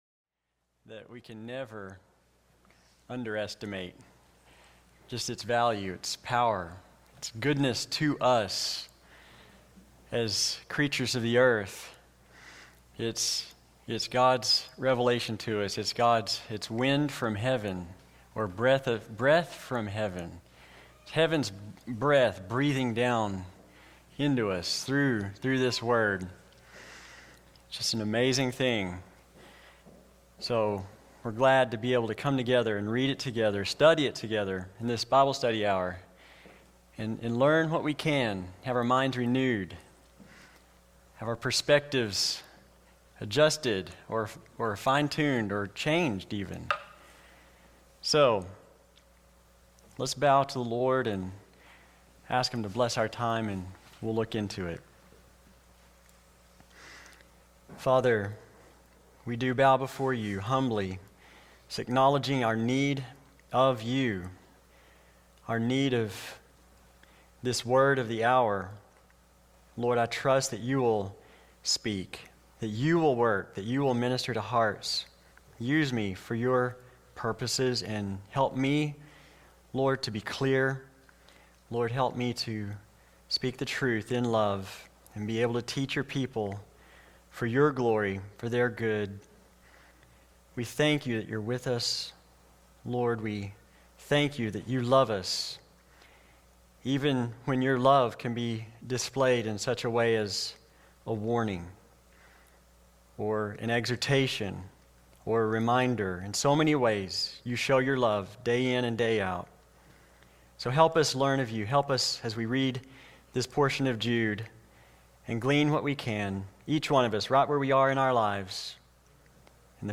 Marked Out For Condemnation | SermonAudio Broadcaster is Live View the Live Stream Share this sermon Disabled by adblocker Copy URL Copied!